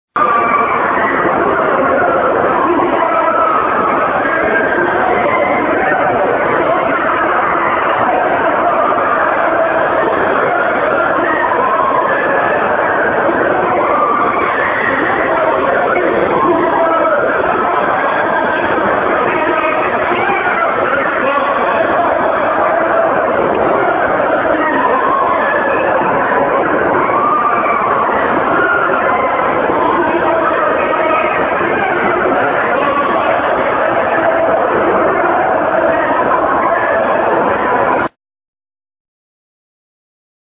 Звуки Ада на 6660
sounds_from_hell.mp3